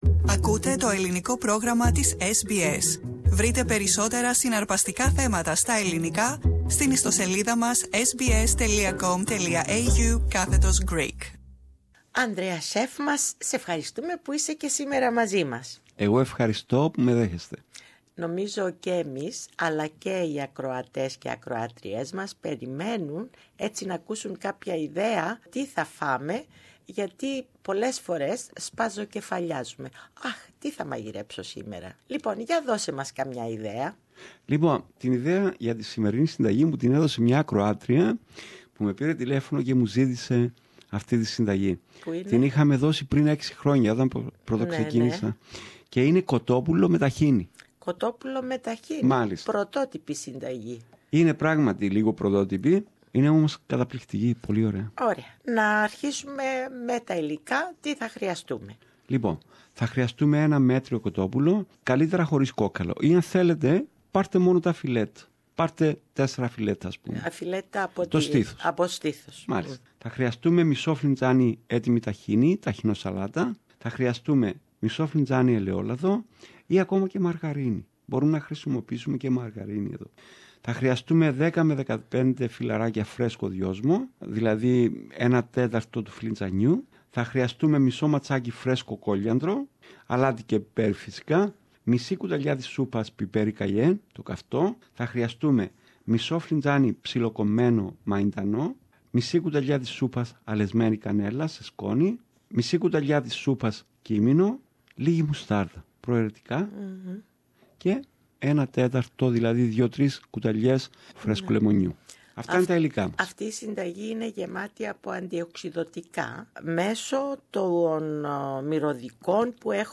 συνομιλία